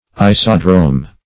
Search Result for " isodrome" : The Collaborative International Dictionary of English v.0.48: Isodrome \I"so*drome\, n. [Iso- + Gr.